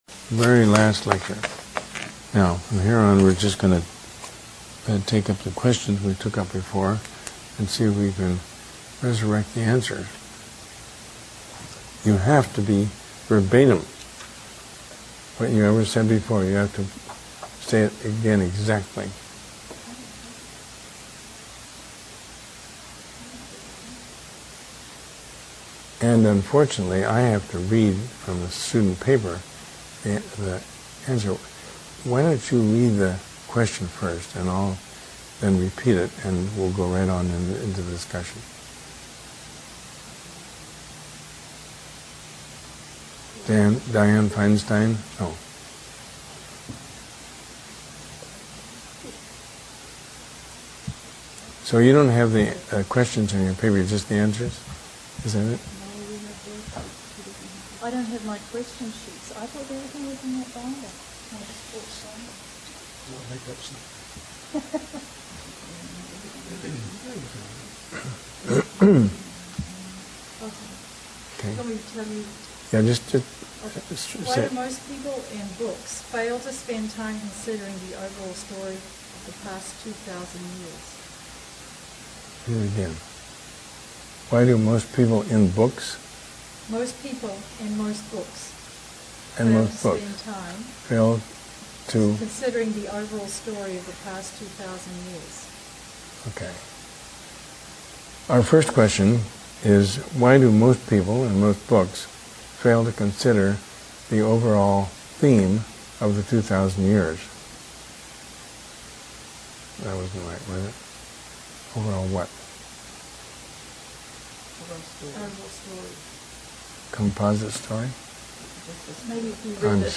Lesson 20 Lecture: Seizing the Future